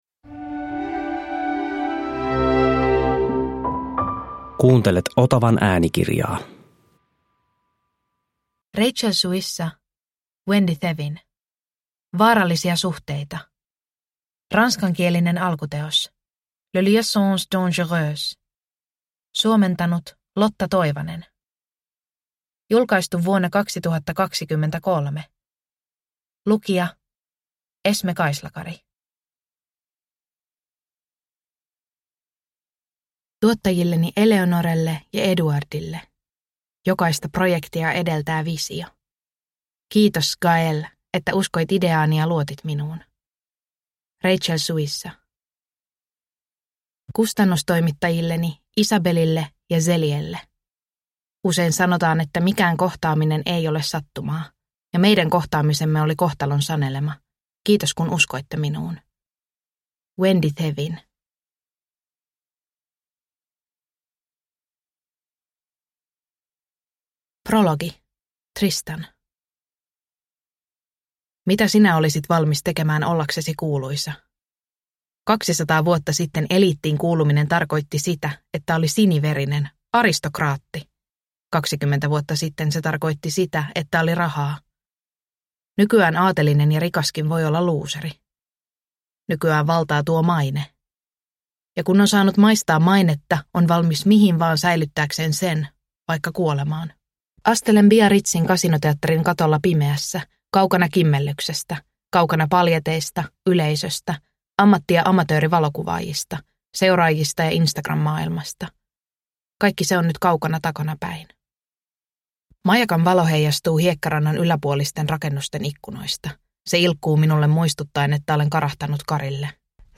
Vaarallisia suhteita – Ljudbok – Laddas ner